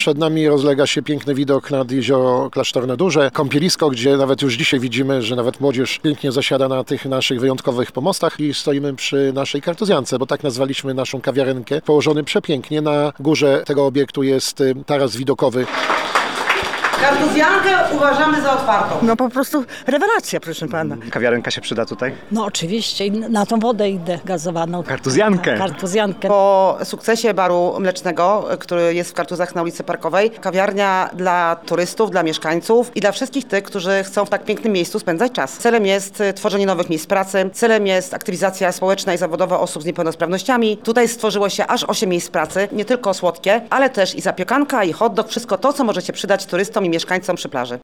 Jak mówi Mieczysław Gołuński, burmistrz Kartuz, to miejsce odpoczynku i spotkań w wyjątkowym miejscu. Dodaje, że miasto niegdyś było kurortem i teraz do tego wraca.